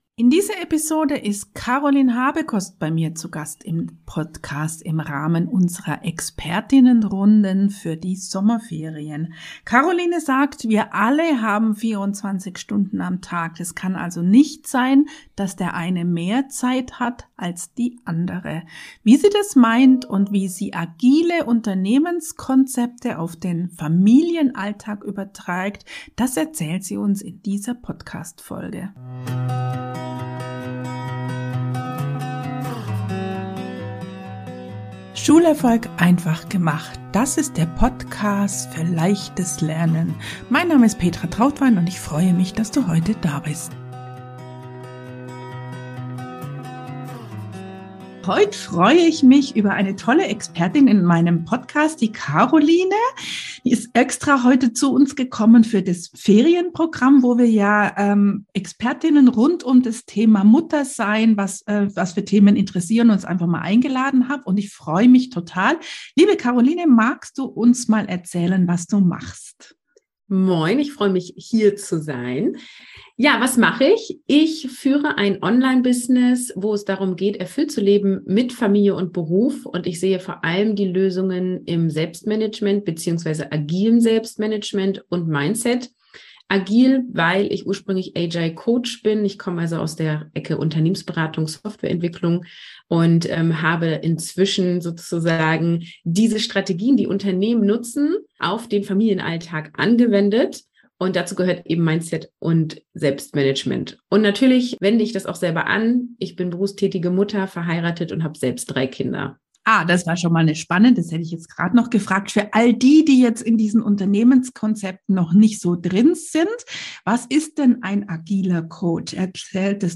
Sommer-Interview